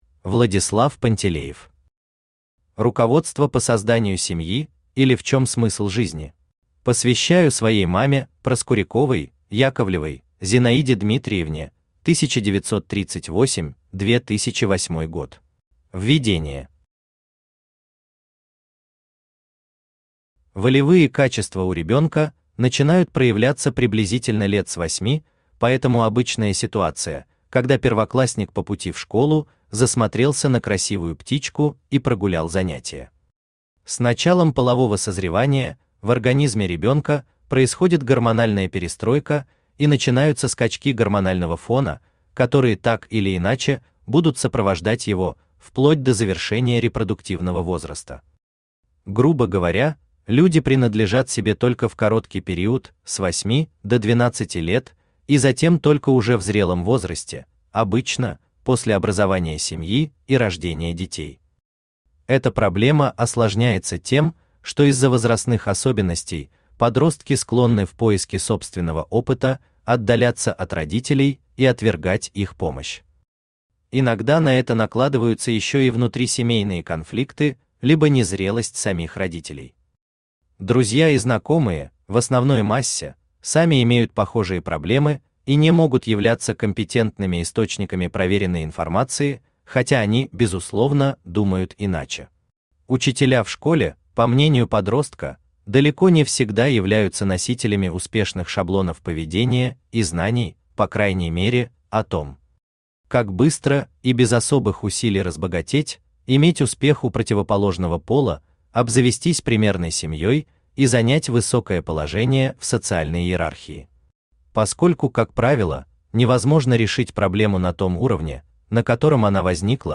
Аудиокнига Руководство по созданию семьи, или В чем смысл жизни?
Автор Владислав Львович Пантелеев Читает аудиокнигу Авточтец ЛитРес.